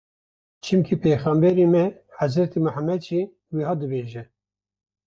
Wymawiane jako (IPA)
/t͡ʃɪmˈkiː/